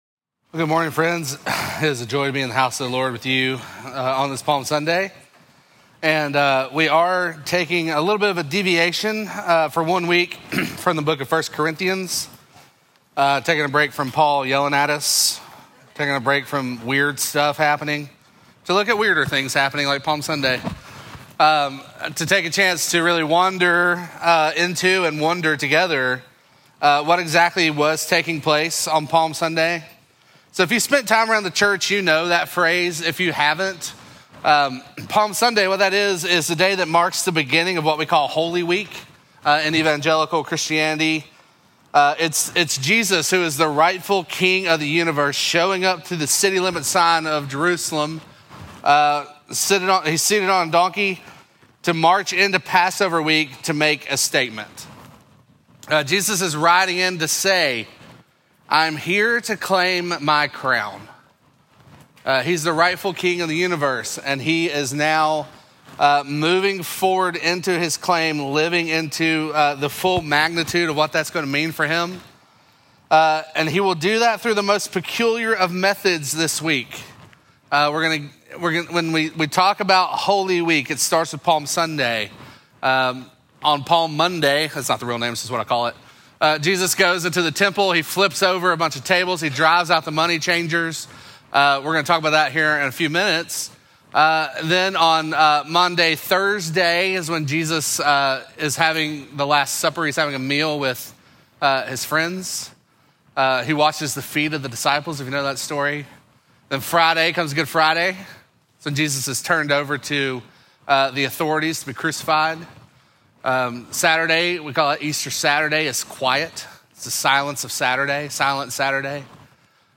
Midtown Fellowship 12 South Sermons The King Is Here: Palm Sunday Apr 13 2025 | 00:27:58 1x 00:00 / 00:27:58 Subscribe Share Apple Podcasts Spotify Overcast RSS Feed Share Link Embed